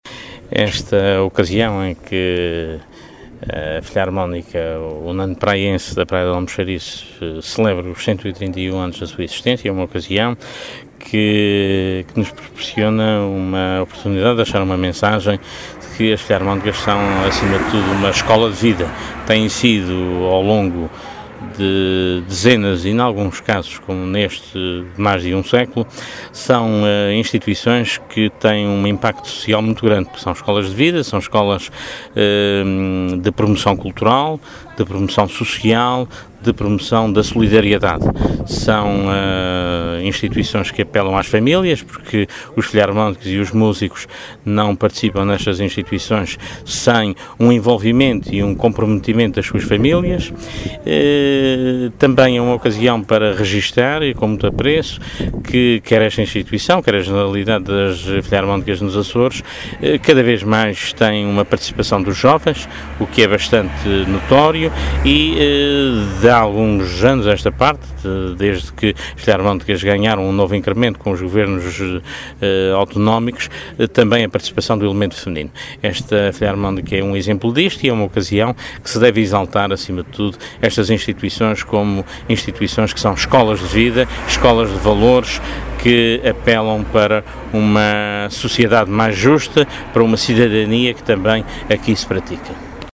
O Diretor Regional da Cultura afirmou ontem à noite, na ilha do Faial, que as filarmónicas açorianas são “escolas de vida e de valores” que apelam para uma sociedade mais justa e para o exercício da cidadania.